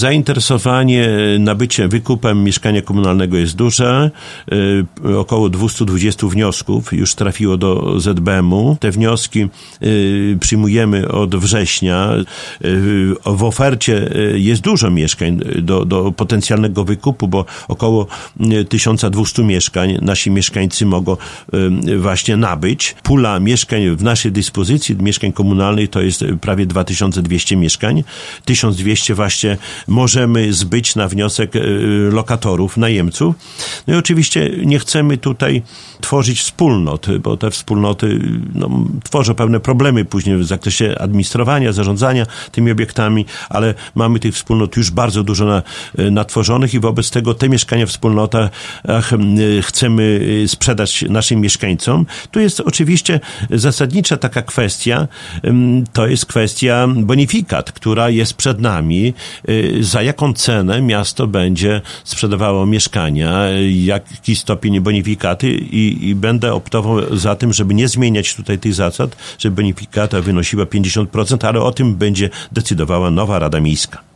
Mówi Czesław Renkiewicz, prezydent Suwałk.